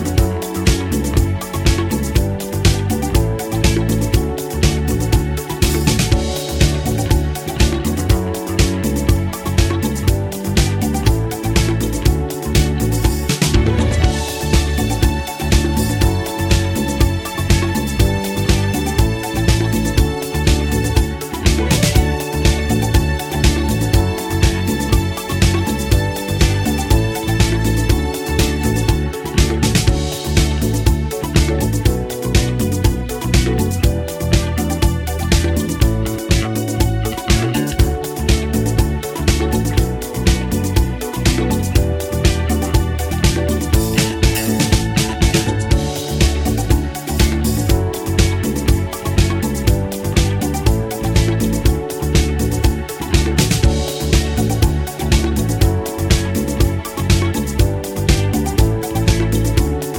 no Backing Vocals Disco 3:11 Buy £1.50